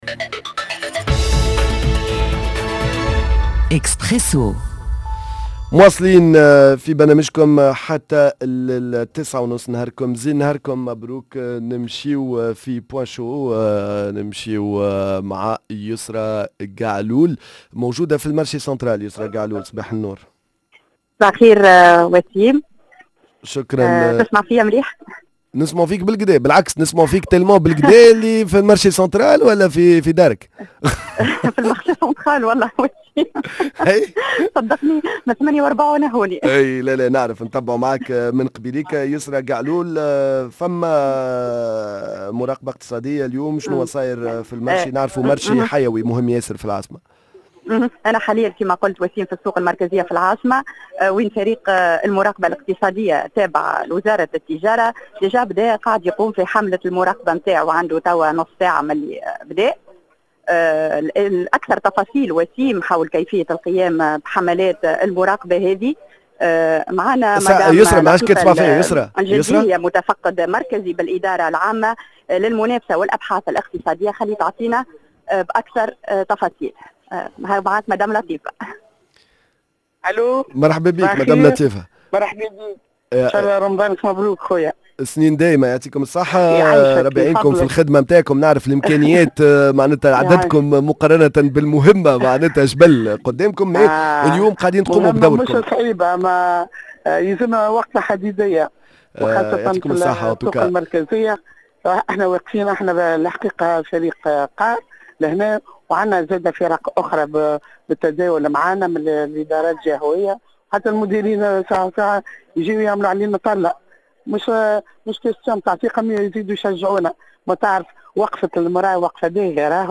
Point_chaud فرق المراقبة الإقتصادية تكثف عمليات الرقابة على الأسواق في شهر رمضان مباشر
من السوق المركزية بالعاصمة